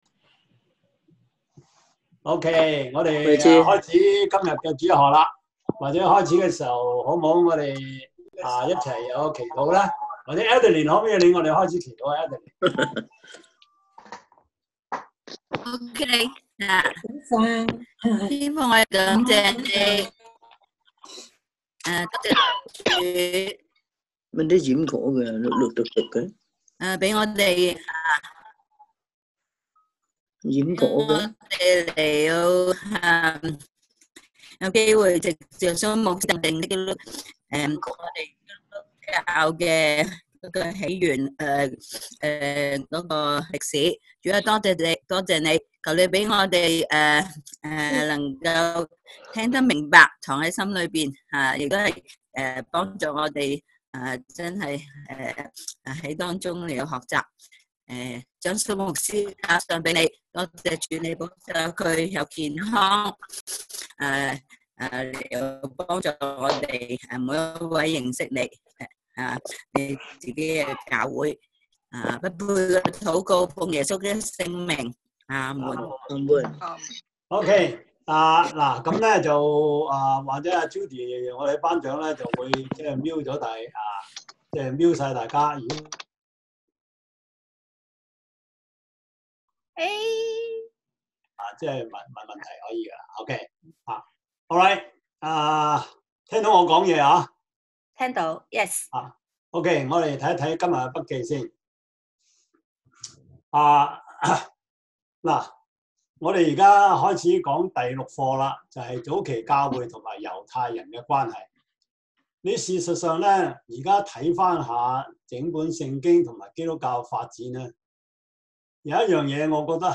Series: 中文主日學, 教會歷史